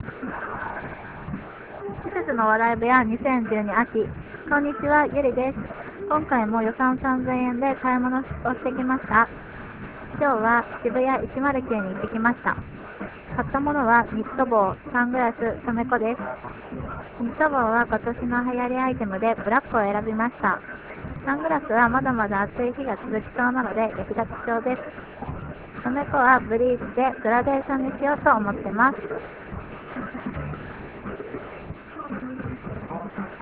リポート等の音声はスタジオ録音ではなく現地録りになりますので他の人の声などの雑音が入っていたりしますがご了承願います。